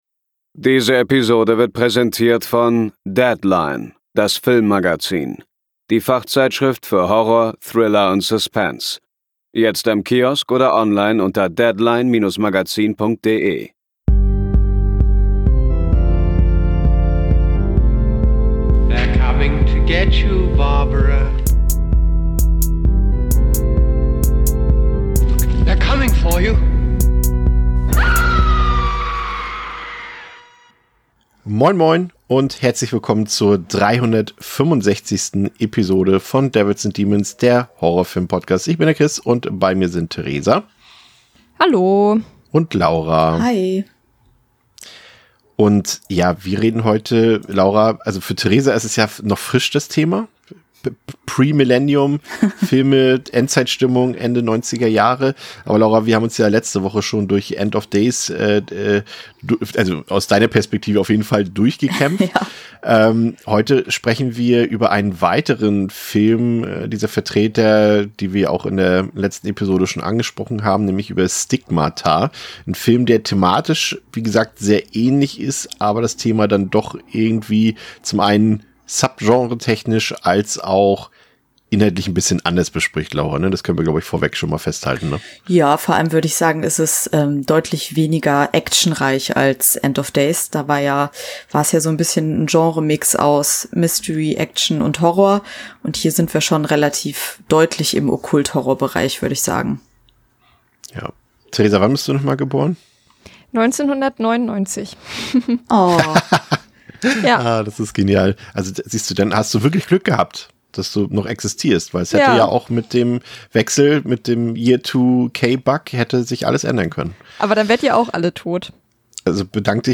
Das Interview hört ihr selbstverständlich in dieser Episode.